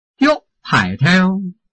臺灣客語拼音學習網-客語聽讀拼-饒平腔-入聲韻
拼音查詢：【饒平腔】giug ~請點選不同聲調拼音聽聽看!(例字漢字部分屬參考性質)